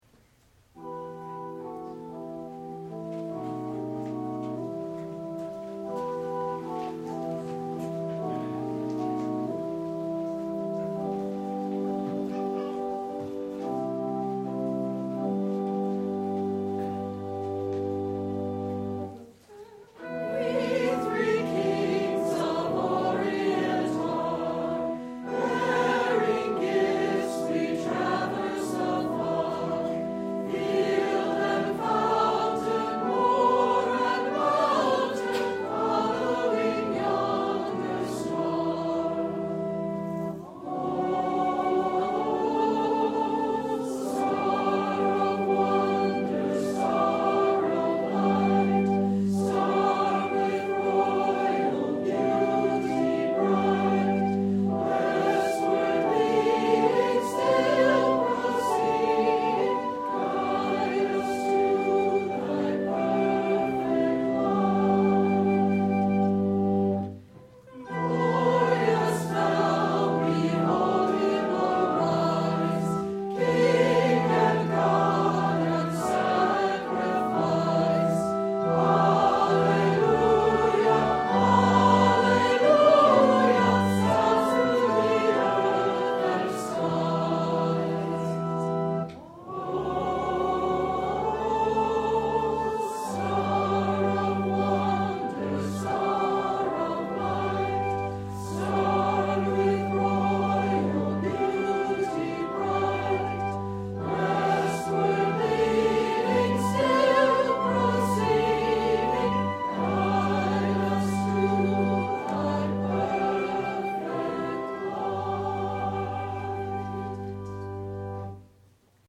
Christmas Eve Service - 2011
We Three Kings (Hymn sung by Choir and Congregation) (1:59)